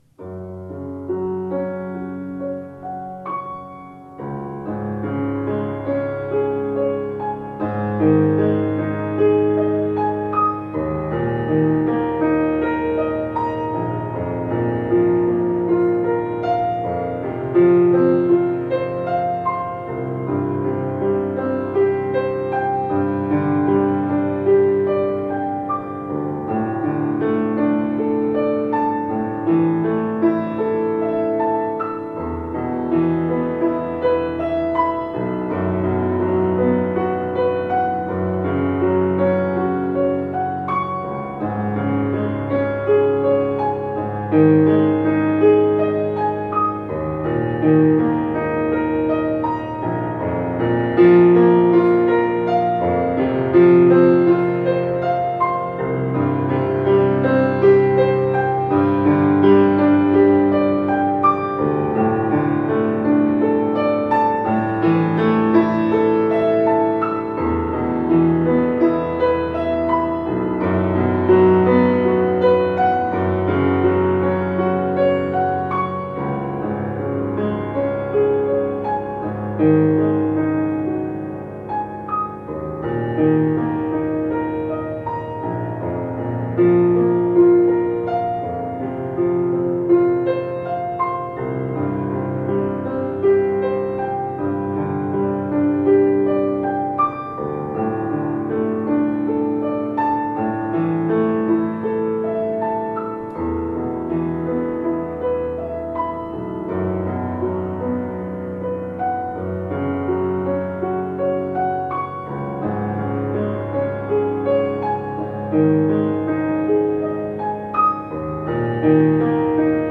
Daher jetzt ein kleiner Praxisteil: Spielt und experimentiert mit zwei, drei und vier benachbarten Quinten.
Quintus mit zwei Nachbartönen im Quintenzirkel